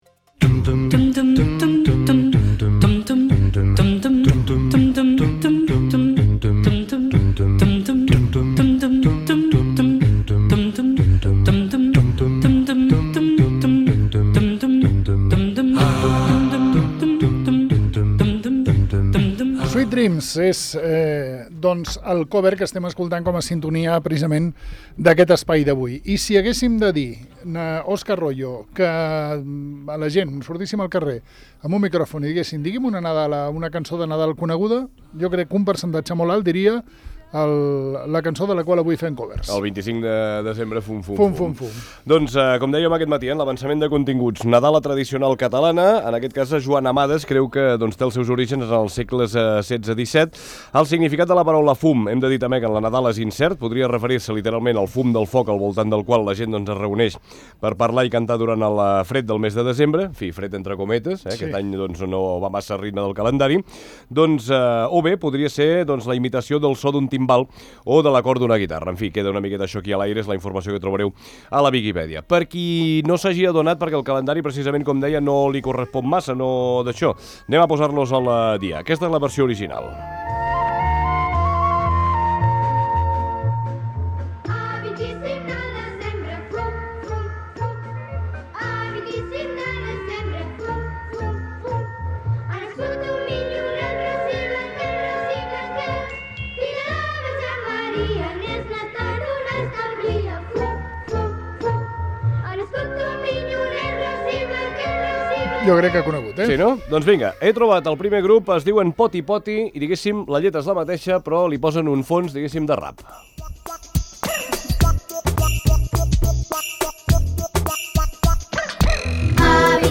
La darrera, a ritme de reggae.